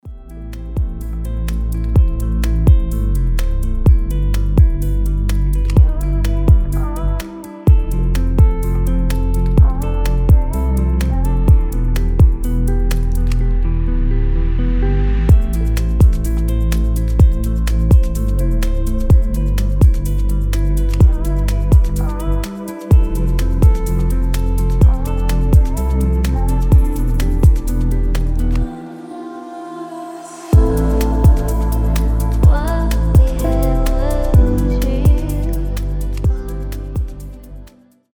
• Качество: 320, Stereo
красивые
атмосферные
спокойные
chillout
Ambient
future garage
chillstep